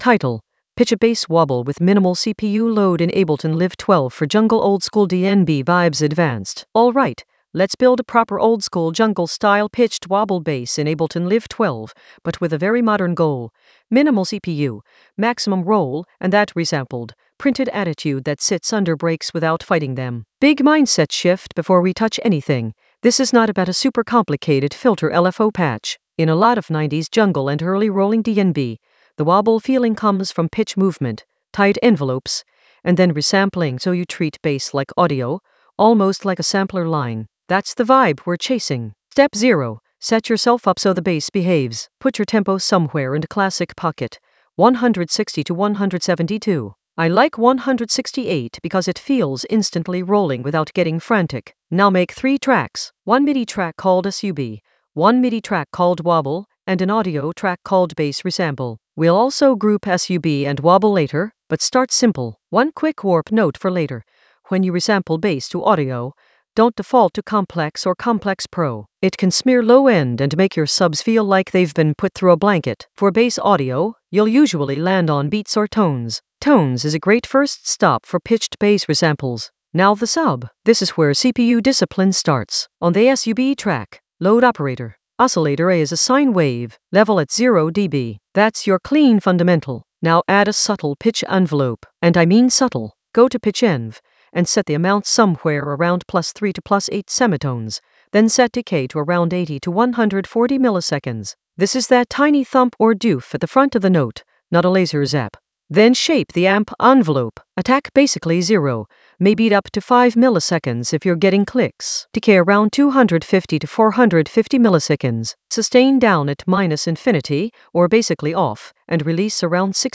Narrated lesson audio
The voice track includes the tutorial plus extra teacher commentary.
An AI-generated advanced Ableton lesson focused on Pitch a bass wobble with minimal CPU load in Ableton Live 12 for jungle oldskool DnB vibes in the Composition area of drum and bass production.